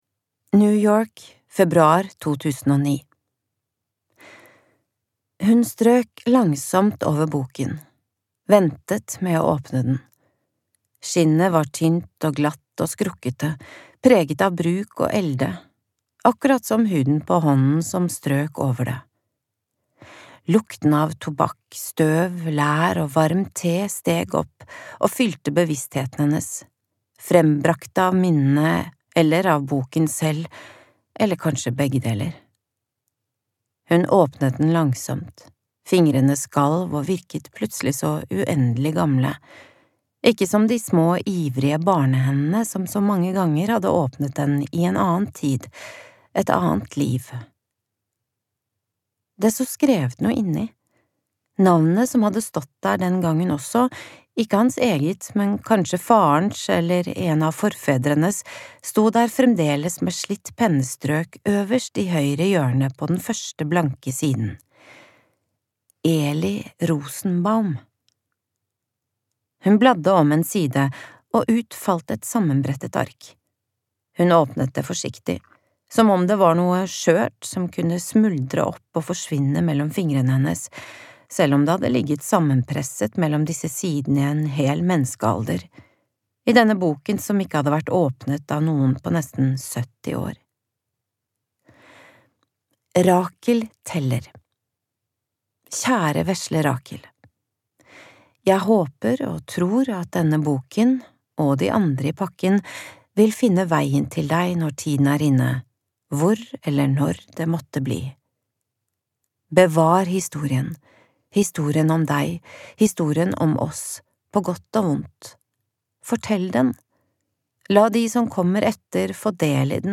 Utdraget er hentet fra lydbokbokutgaven